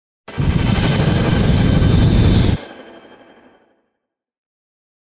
mandrake fvttdata/Data/modules/psfx/library/ranged-magic/generic/missile/001